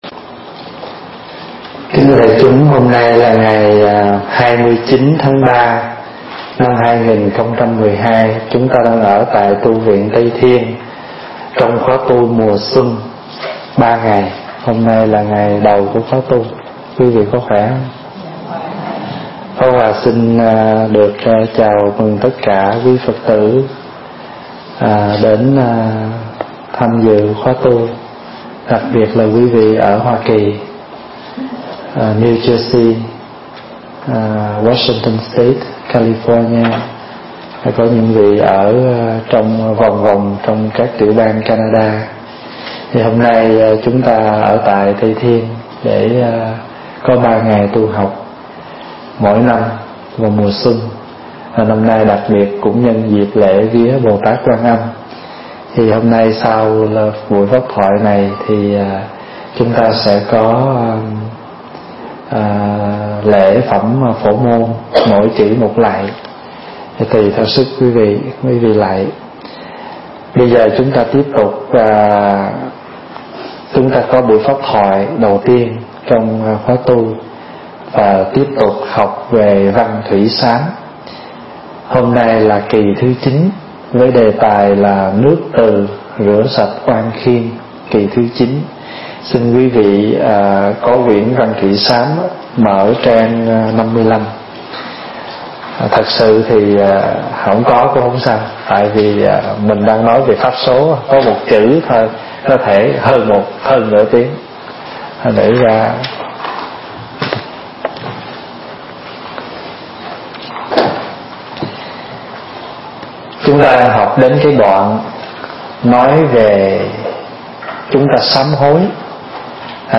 Mp3 Pháp Thoại Nước Từ Rửa Sạch Oan Khiên 9 – Đại Đức Thích Pháp Hòa thuyết giảng tại Tu Viên Tây Thiên, Canada, khóa tu mùa xuân, ngày 29 tháng 3 năm 2013